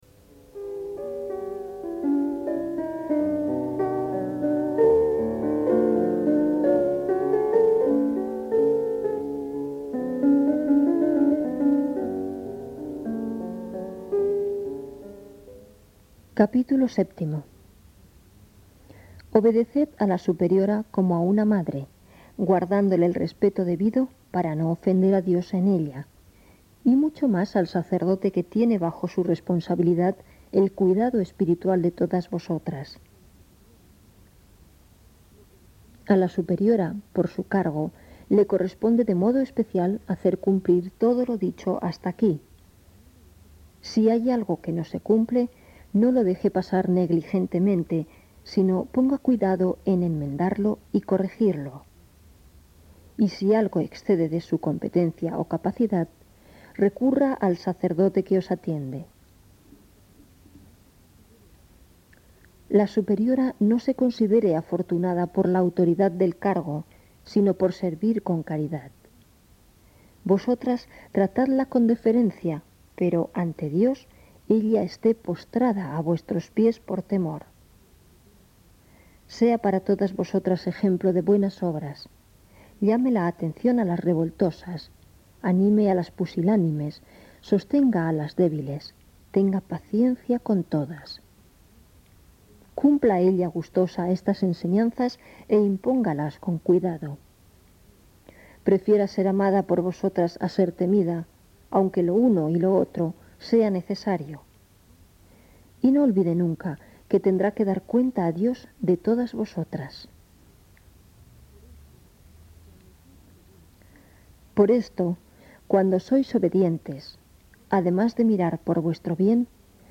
Voz de mujer.